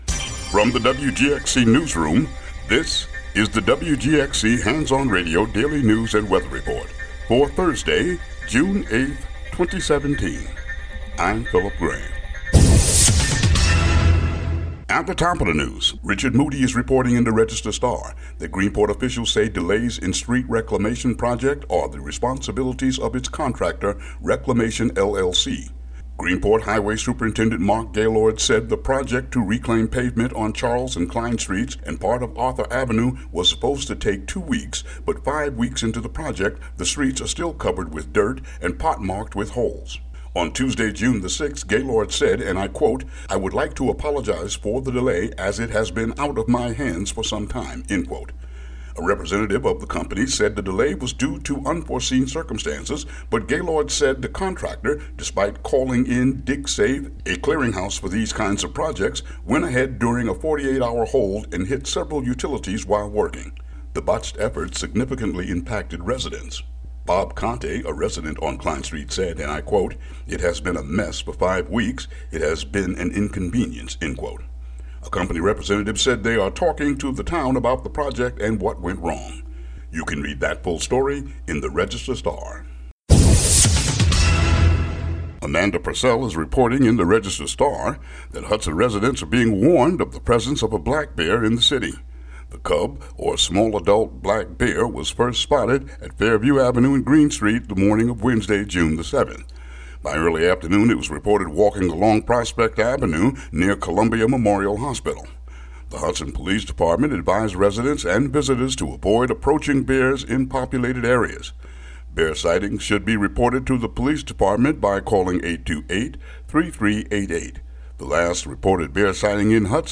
WGXC daily headlines for Jun. 8, 2017.